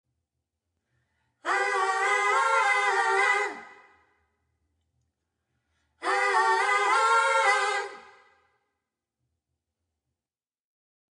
sorry for got to inlude the mp3 vietnames call " nhac be`"